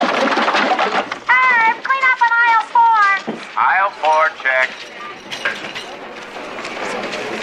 • Woman on PA system - Clean up on aifle 4.ogg